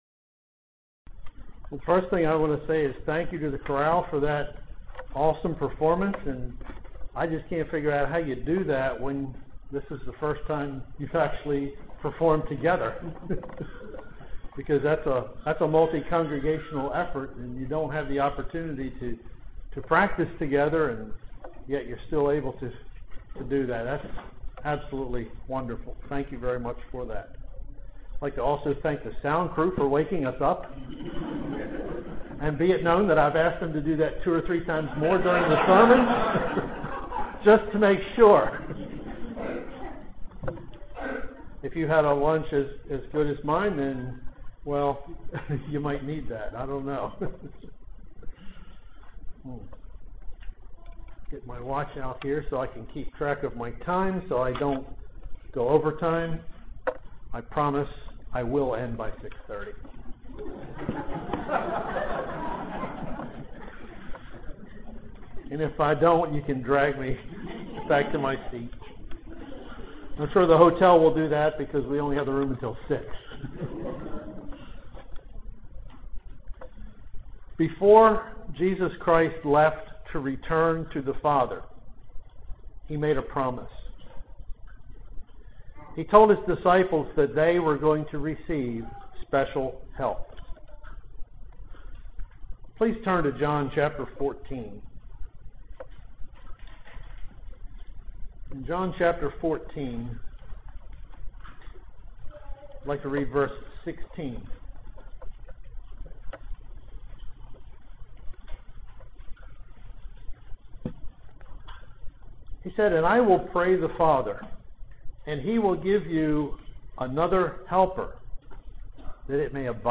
Given in Columbia, MD
UCG Sermon Studying the bible?